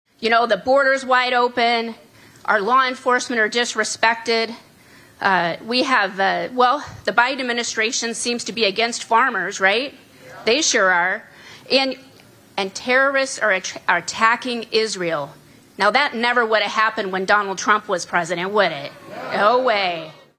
Bird made the announcement just before introducing Trump to a crowd in Adel this (Monday) afternoon.